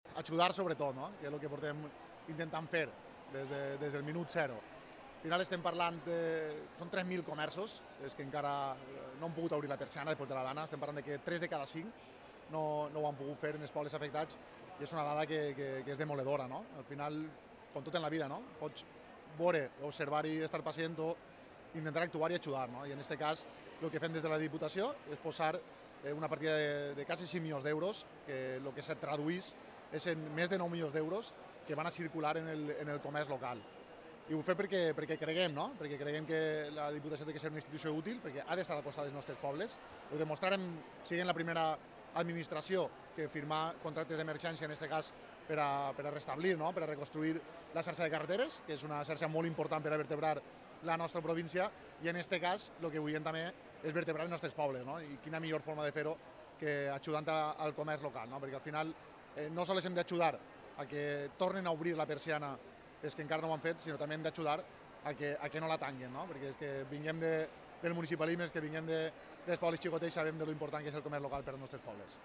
El Mercat d’Aldaya ha acogido un acto en el que el presidente de la Diputación, Vicent Mompó.
Vicent_Mompo_President.mp3